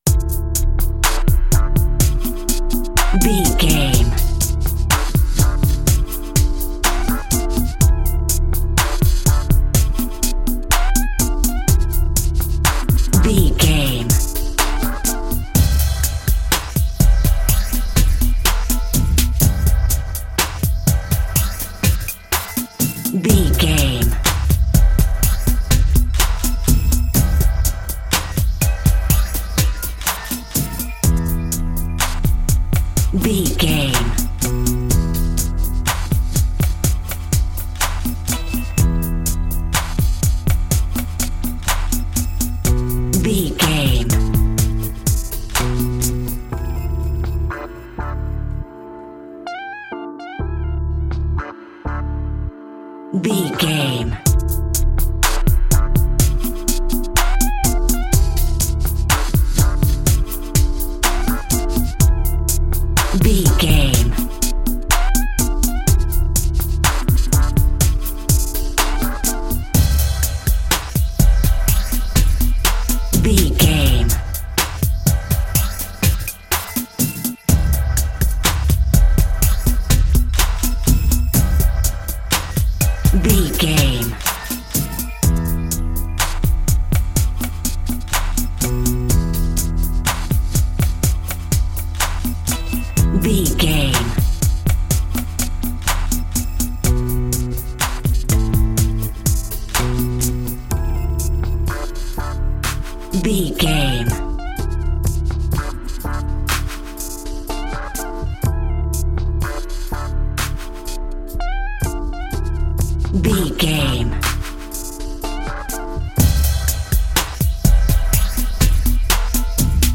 Aeolian/Minor
synthesiser
drum machine
funky